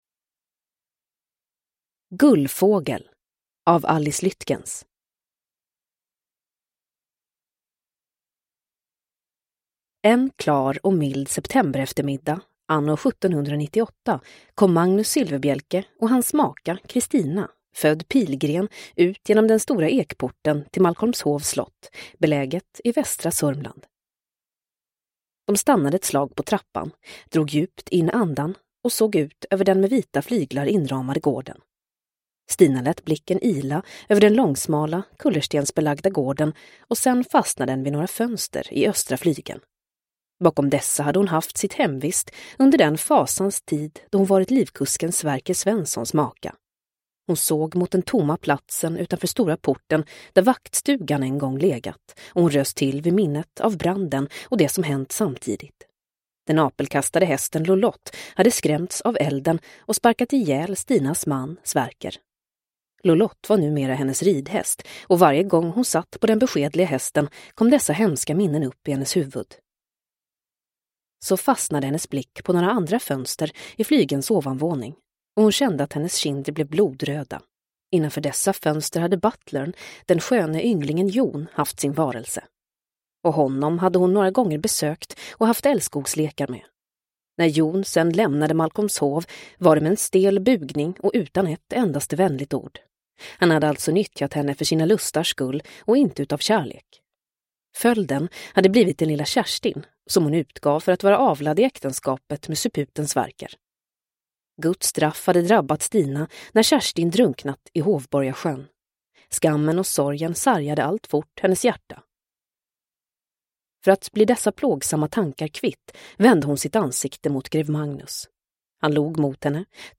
Gullfågel – Ljudbok – Laddas ner